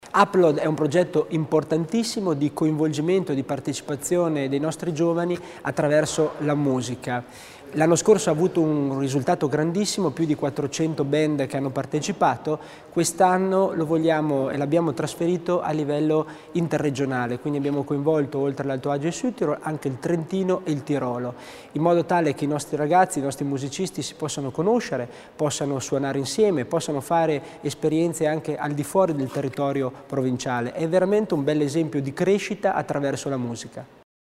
L'Assessore Tommasini illustra l'importanza di un progetto come Upload